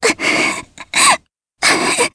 Jane-Vox_Sad_jp.wav